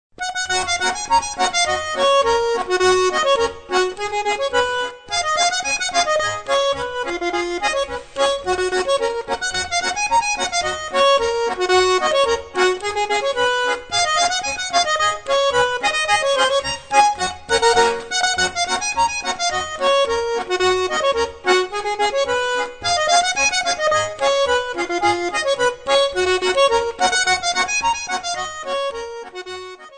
Stücke der originalen Volksmusik berücksichtigt.